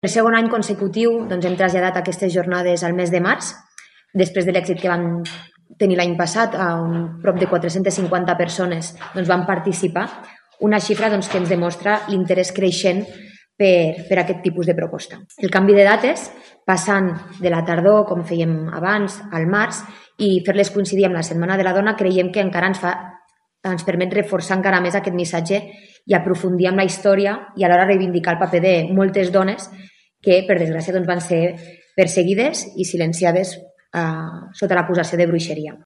L’esdeveniment se celebrarà del 6 a l’11 de març a la Sala Sergi Mas de Sant Julià de Lòria i, per segon any consecutiu, es trasllada al mes de març per fer-lo coincidir amb la Setmana de la Dona. Ho ha explicat la cònsol menor de Sant Julià de Lòria, Sofia Cortesao.